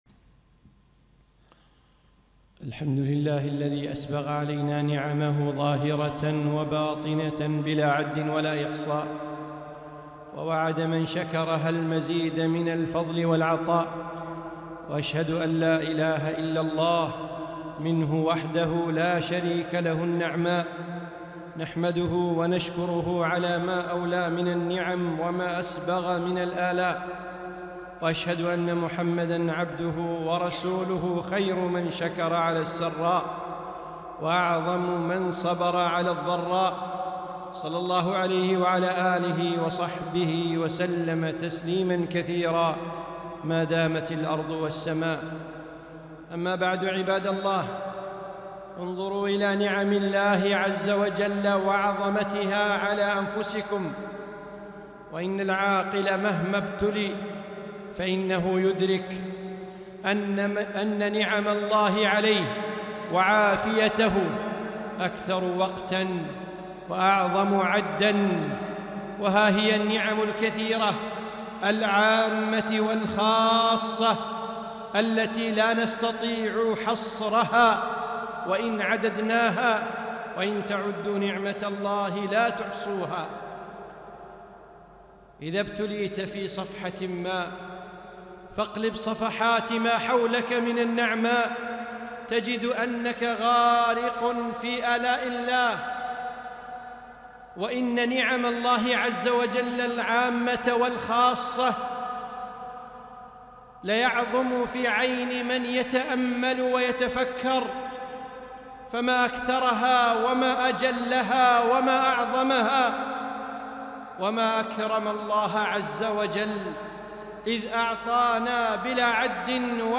خطبة - عظم نعم الله